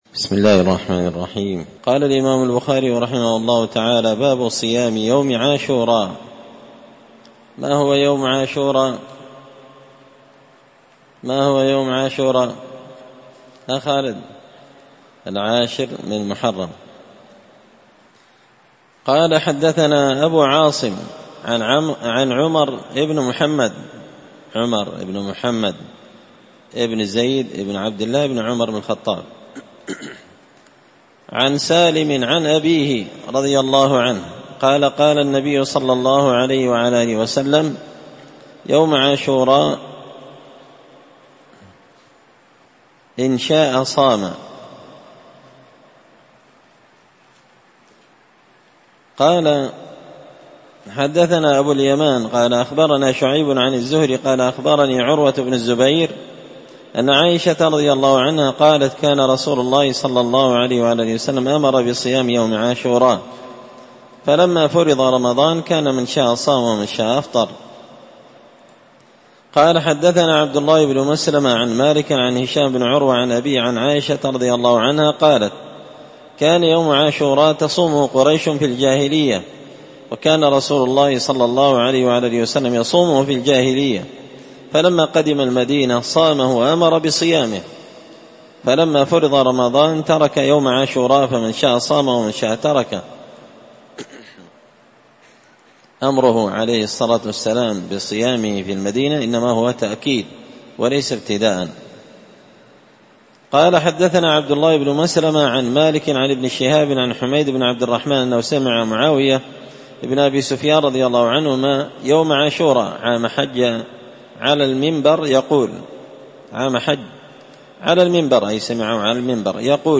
الدرس الرابع والخمسون والأخير (54 والأخير) باب صوم يوم عاشوراء